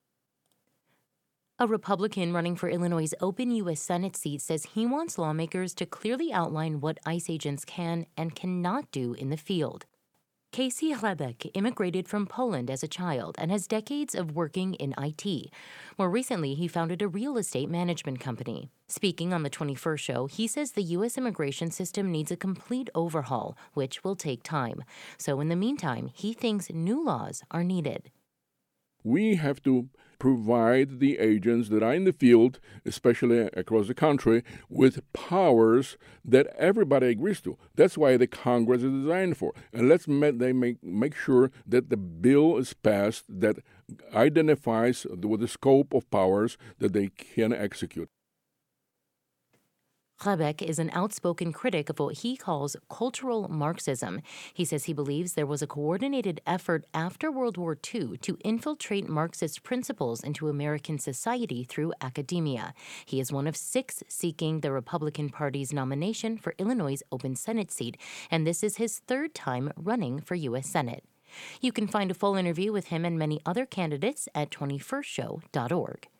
Interview Highlights